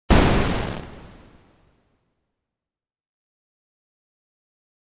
SE（爆発）
爆発。 バーン。 ばーん。ドカーン。 どかーん。